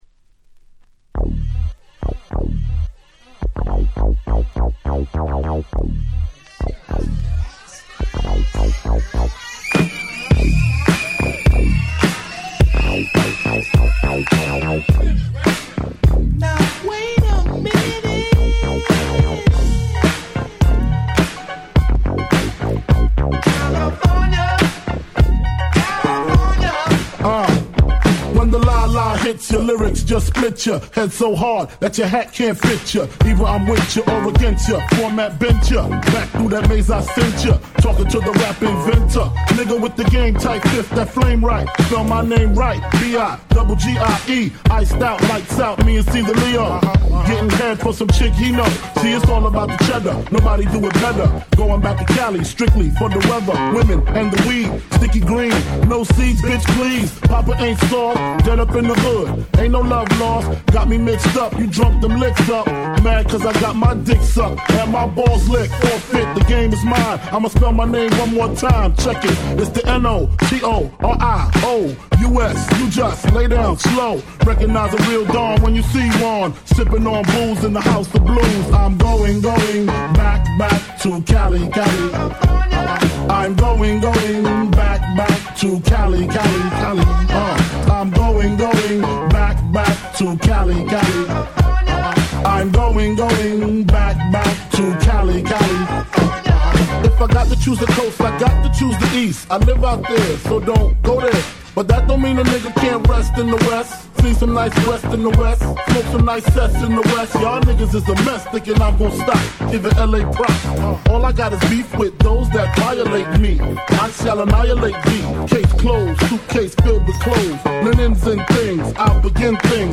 25' Very Nice Remix !!